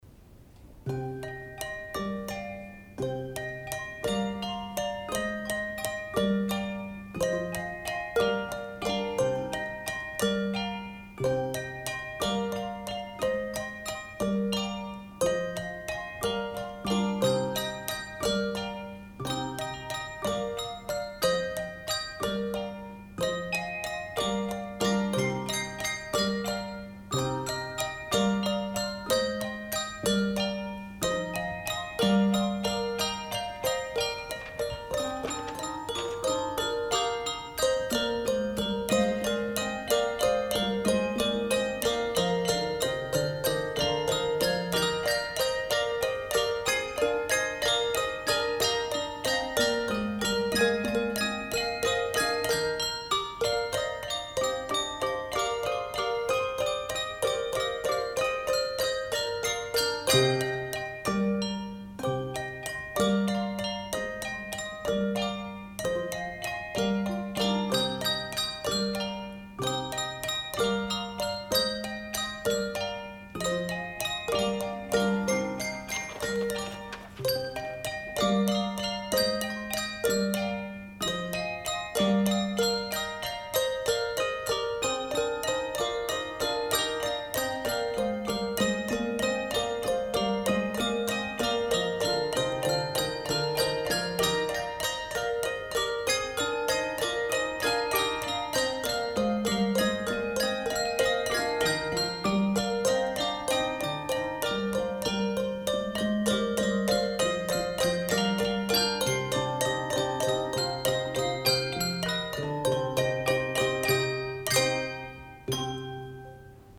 Percussive Praise, Handbell Choir
Performer:  Handbell Choir